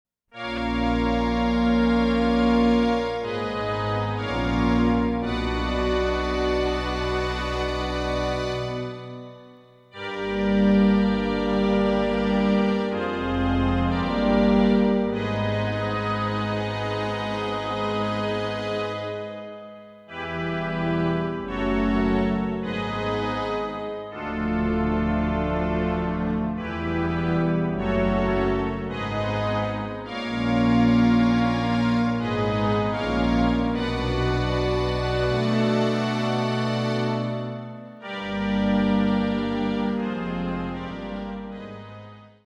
Prophet V, Polymoog, Drums, Finger Bells and Voice